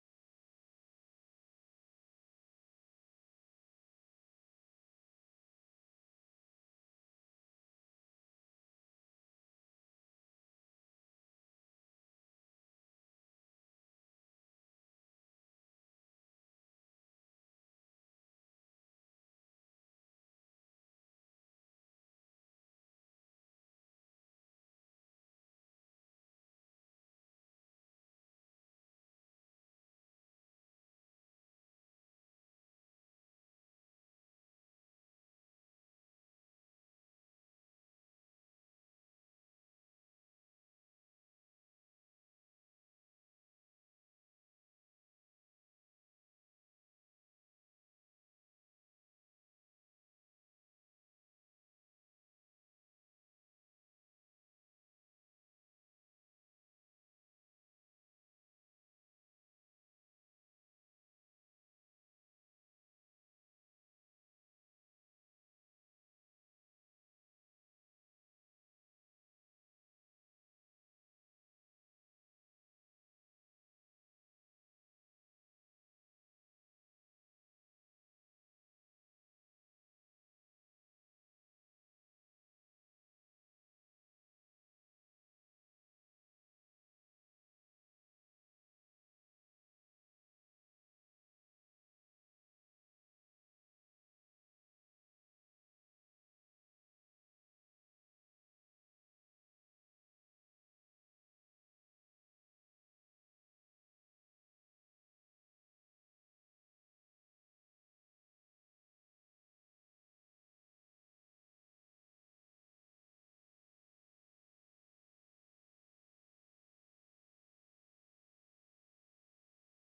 Sermon Series: Follow Me